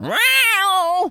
Animal_Impersonations
cat_scream_02.wav